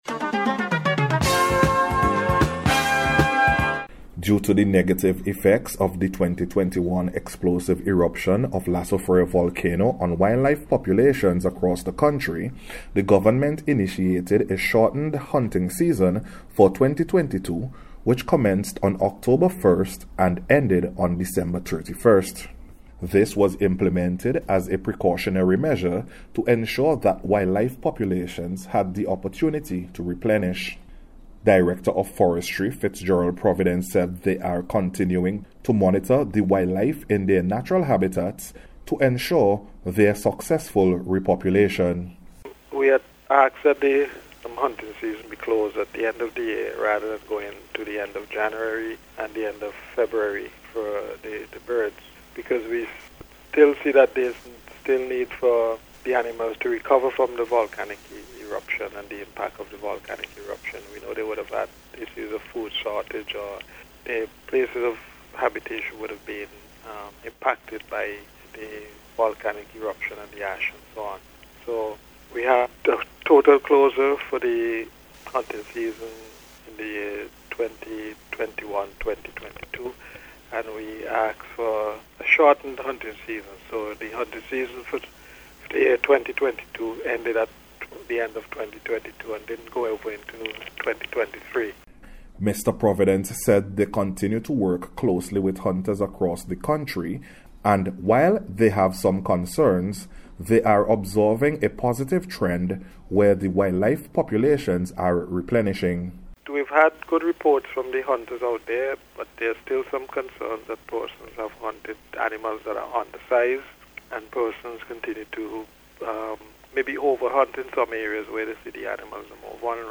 FORESTRY-AND-WILDLIFE-MONITORING-REPORT.mp3